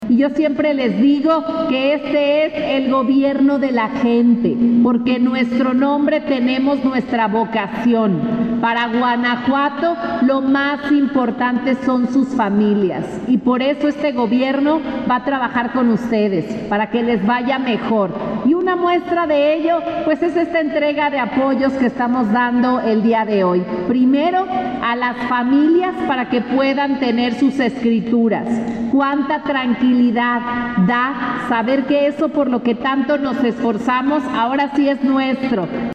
AudioBoletines
Libia Denisse García Muñoz, Gobernadora